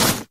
default_snow_footstep.2.ogg